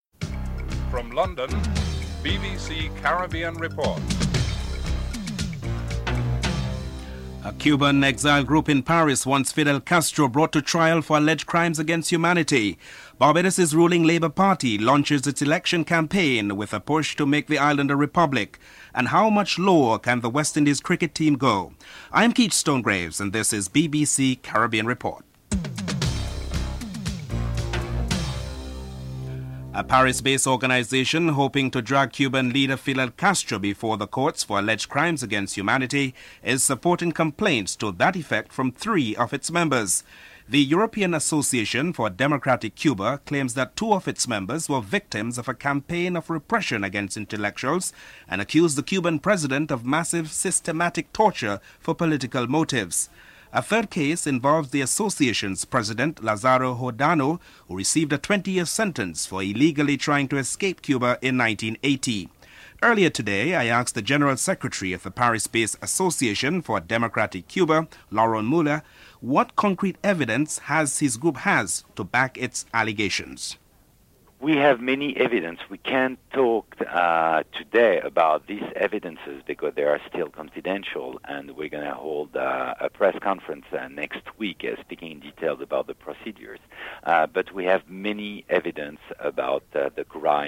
1. Headlines (00:00–00:28)
5. The woeful performance by the West Indies cricket team has left their fans and commentators calling for urgent action. Clive Lloyd speaks. Colin Croft is interviewed (12:25–15:23)